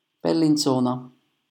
Bellinzona (/ˌbɛlɪnˈznə/ BEL-in-ZOH-nə,[4] Italian: [bellinˈtsoːna]